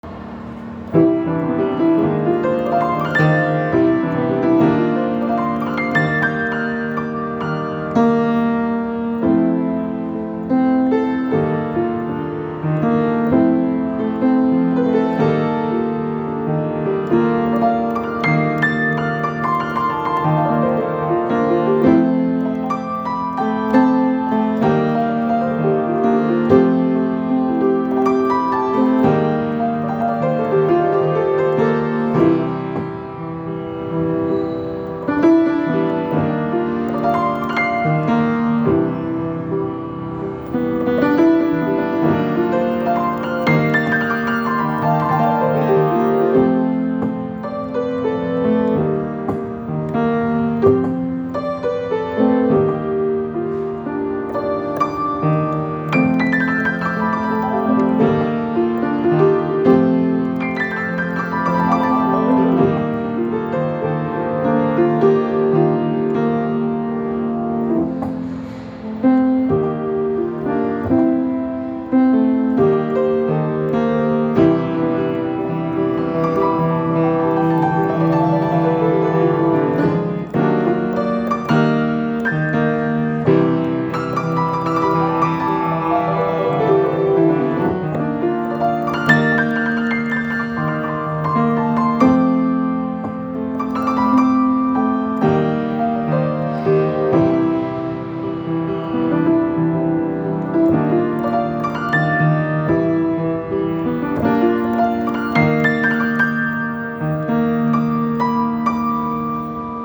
L’intérêt pour le piano l’amène à développer une recherche musicaux en accords 7+11, avec des suggestions chromatique jazz, accents rythmiques swing et l’utilisation du contrepoint libre.
Piano-Jazz-Web.mp3